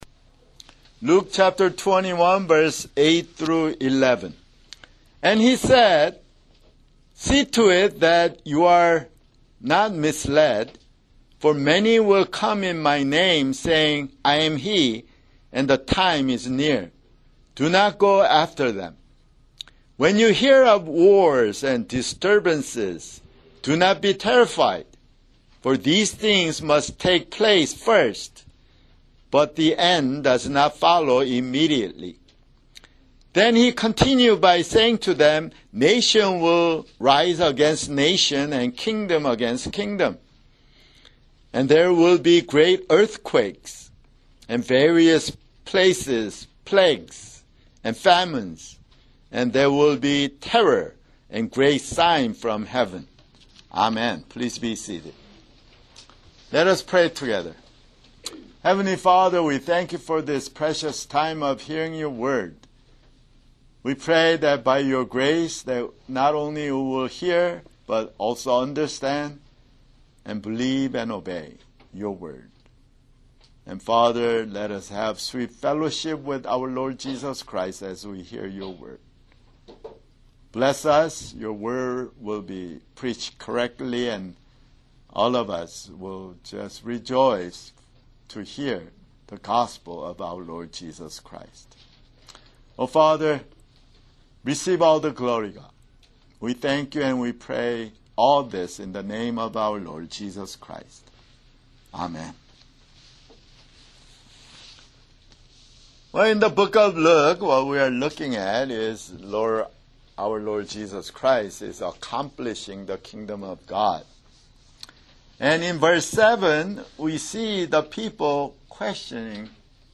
[Sermon] Luke (140)